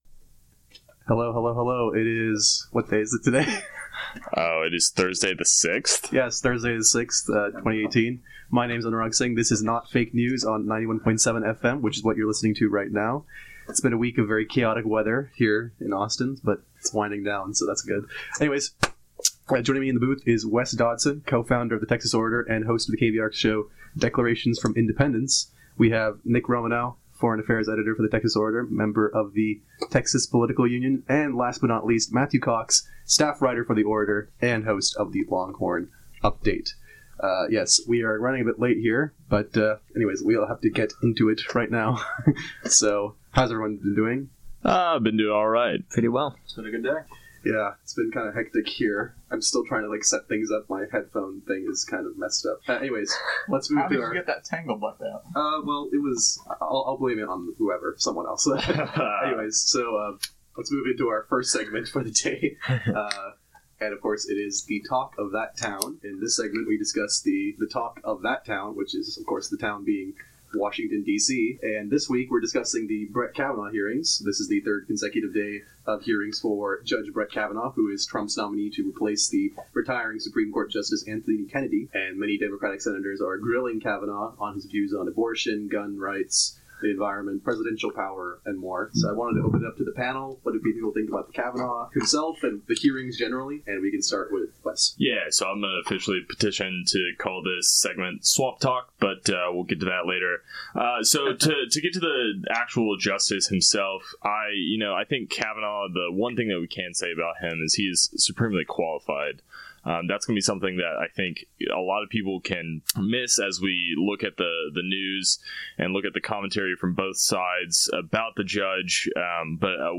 Instead we are talking about: -The Brett Kavanaugh nomination process. -A caller chimes in keyed up about cannabis. -Four guys talk about Betsy Devos' changes to the Title IX regulations.